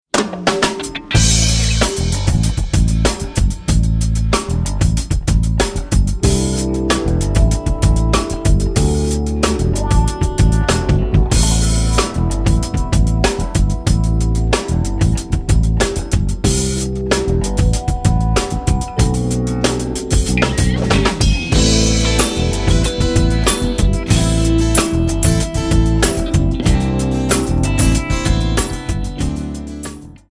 Alternative,Blues,New Age,Rock